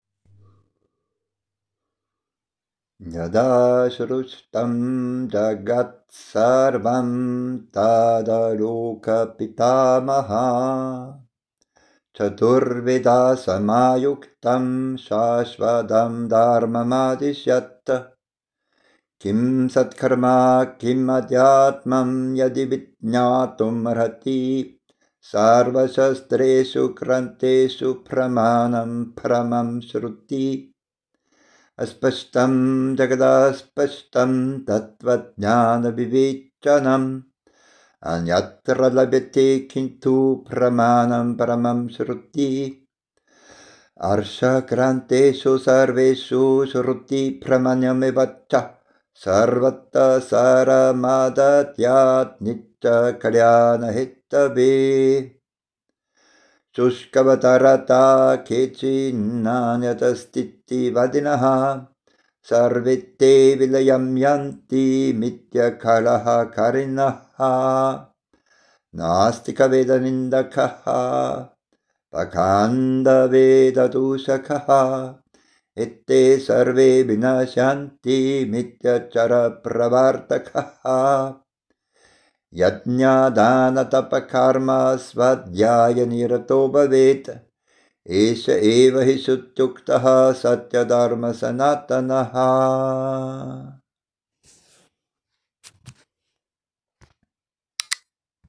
Traditionell werden diese nach Agnihotra gesungen.